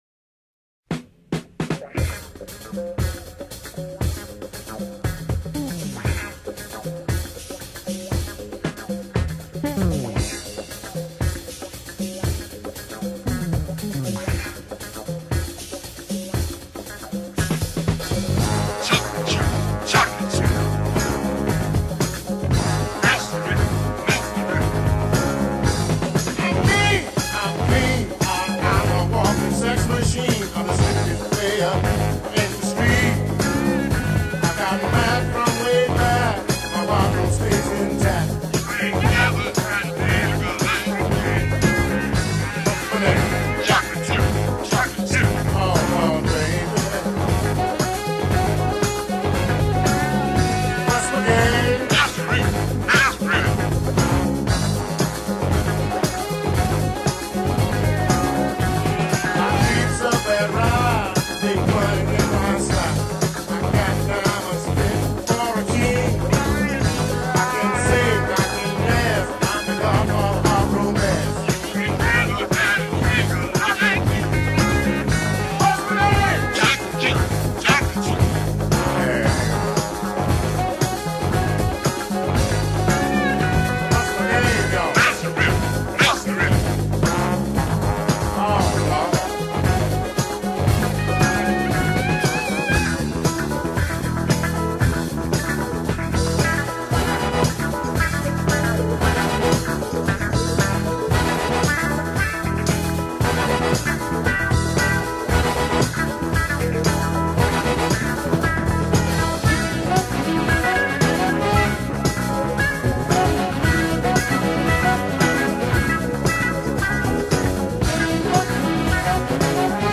Classic soul and funk to change your world.
FunkMusicRomanceSoul